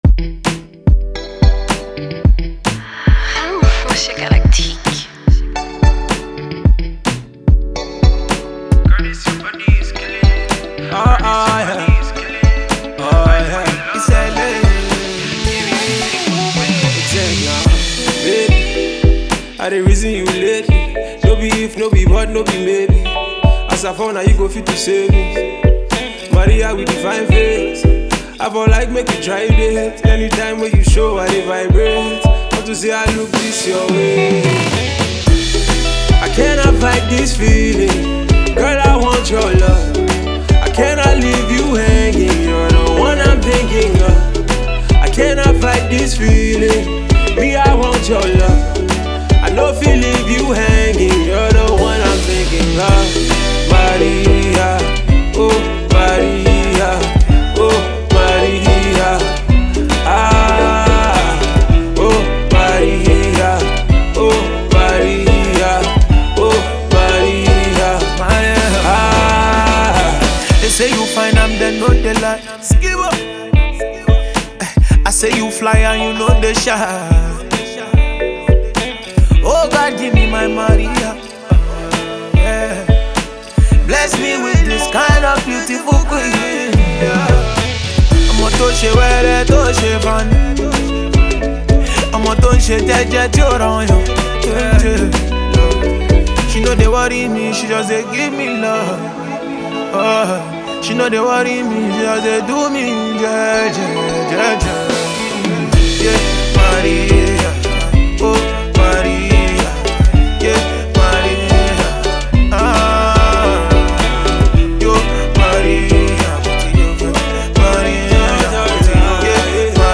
Lead guitar
muted guitar
additional vocals